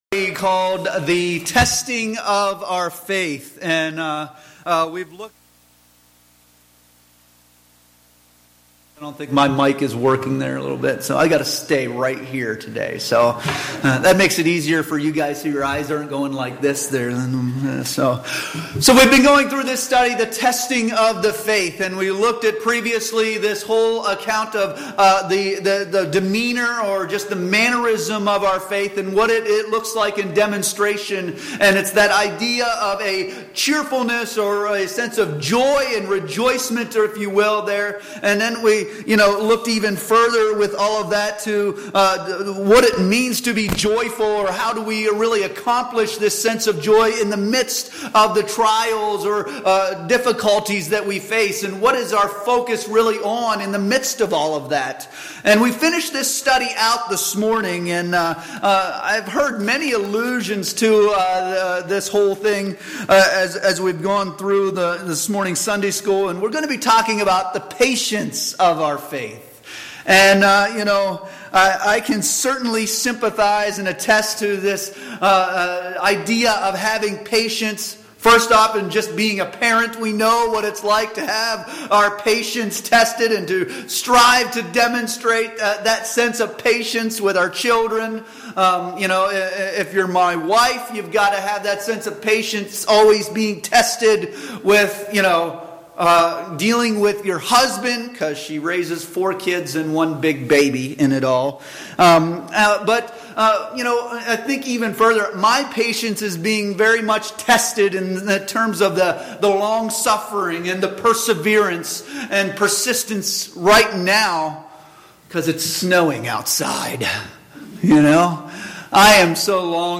James 1:1-4 Service Type: Sunday Morning Worship Bible Text